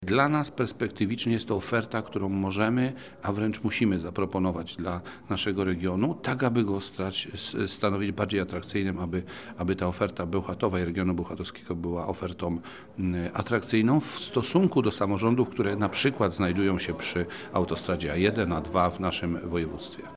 Wiceprezydent miasta Bełchatów, Dariusz Matyśkiewicz, mówi, że podpisana w tym tygodniu umowa na projekt realizowany dzięki rządowemu programowi KolejPlus, to ważny krok, który w procesie transformacji energetycznej regionu, nabiera dodatkowego znaczenia.